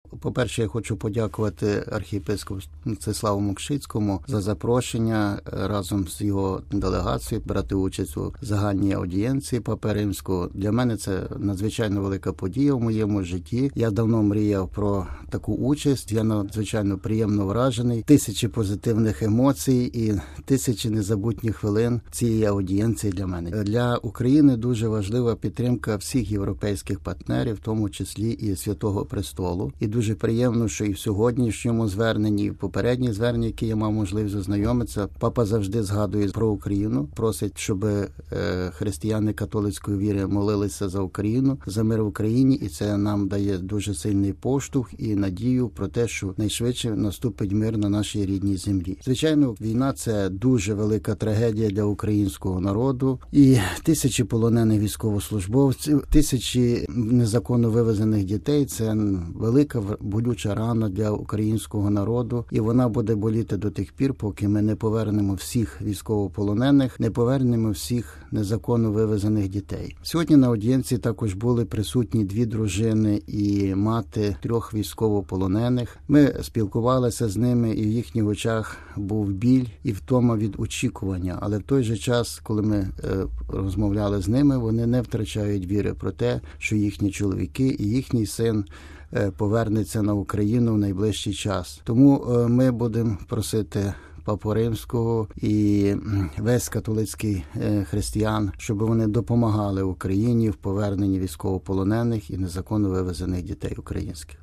В коментарі для нашої редакції він сказав: